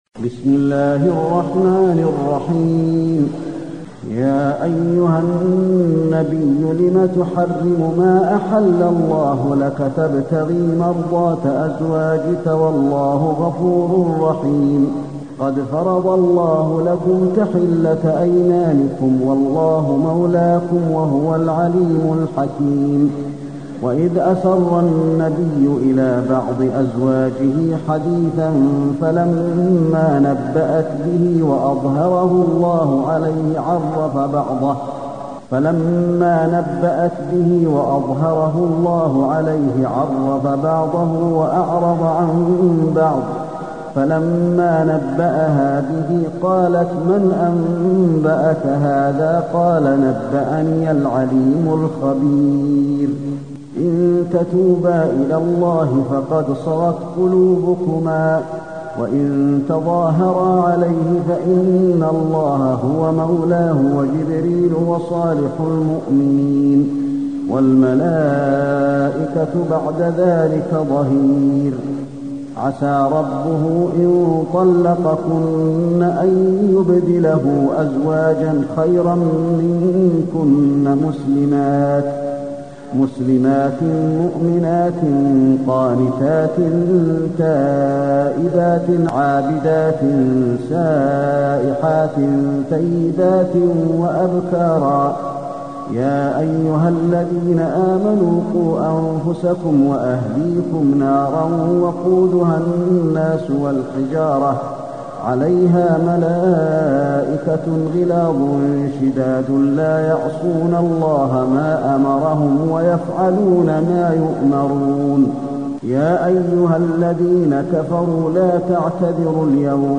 المكان: المسجد النبوي التحريم